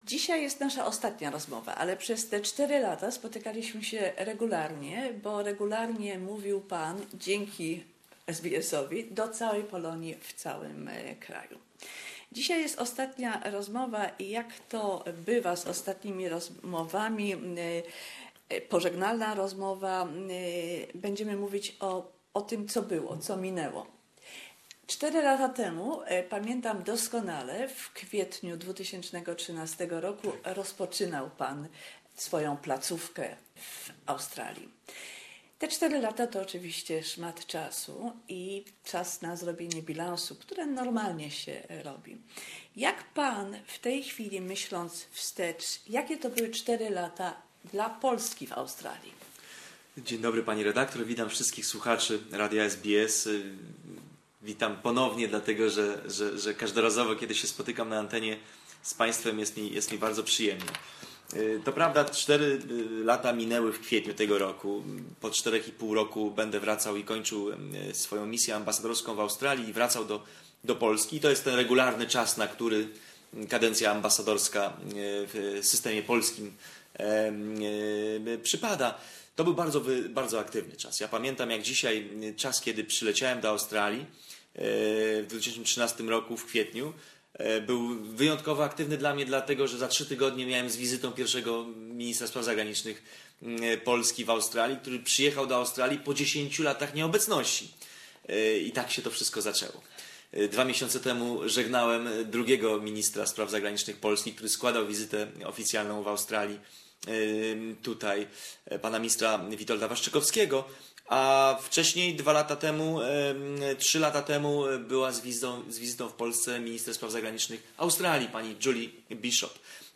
Ambassador Paweł Milewski returns to Poland. Conversation - a summary of four years representing Poland on antipodes.